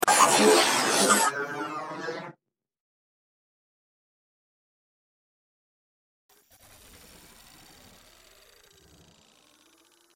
دانلود صدای ربات 68 از ساعد نیوز با لینک مستقیم و کیفیت بالا
جلوه های صوتی